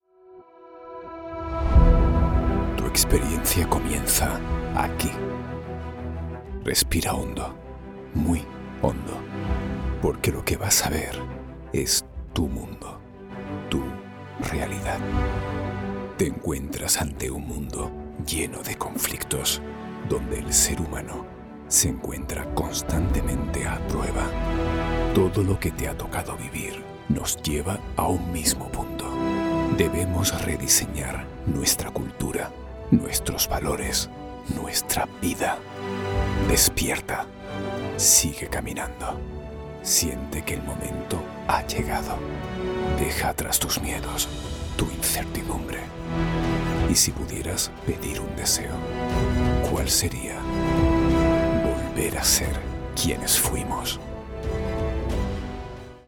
Ankündigungen
Ich kann mit englischem und andalusischem Akzent sprechen.
BaritonTiefNiedrig